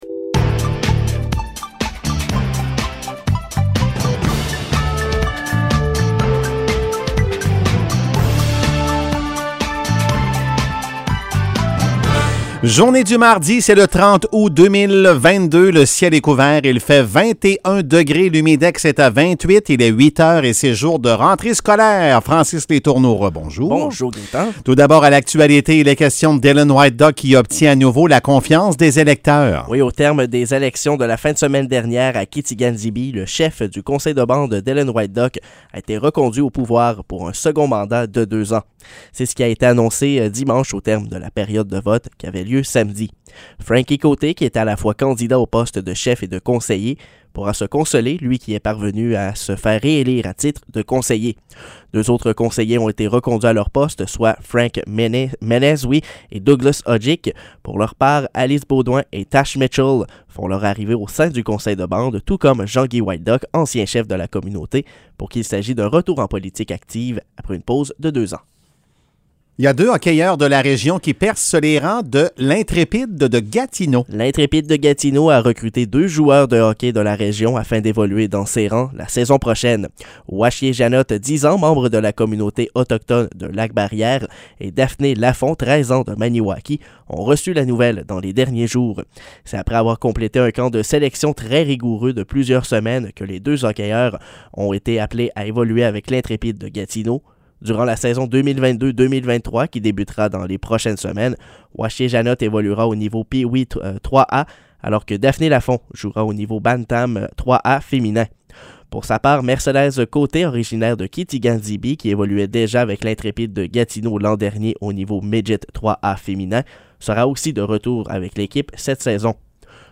Nouvelles locales - 30 août 2022 - 8 h